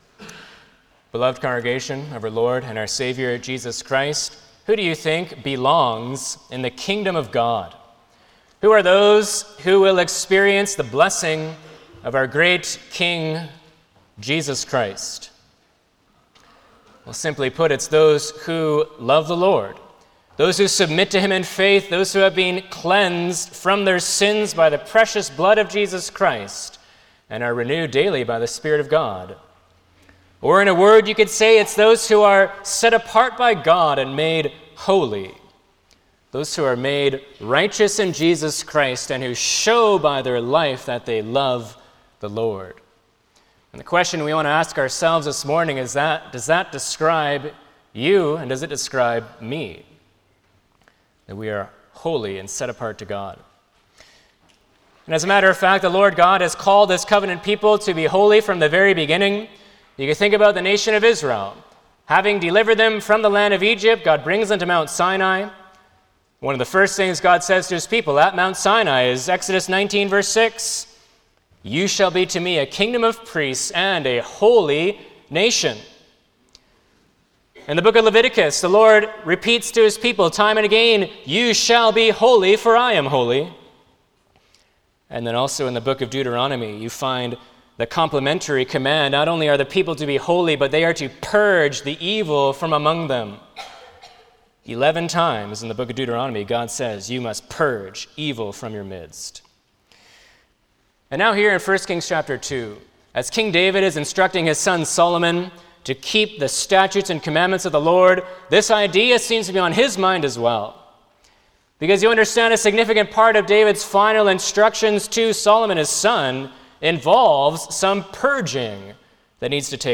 Passage: 1 Kings 2 Service Type: Sunday morning
09-Sermon.mp3